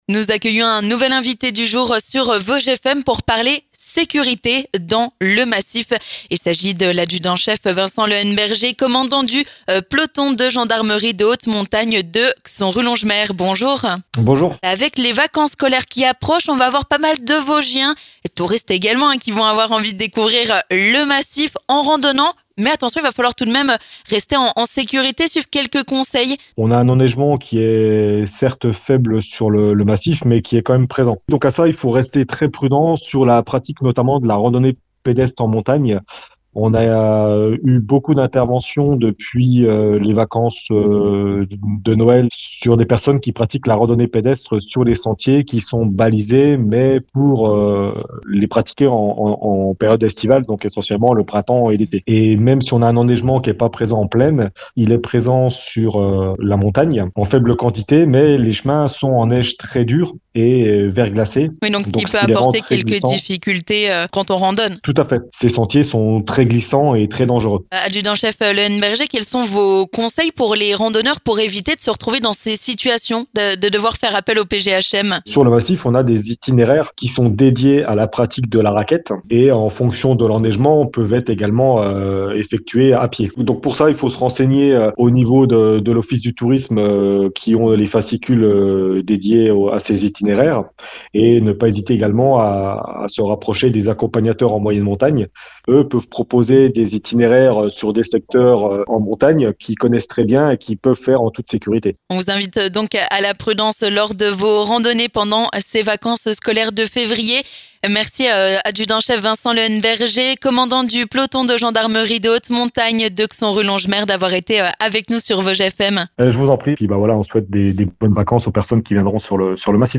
L'invité du jour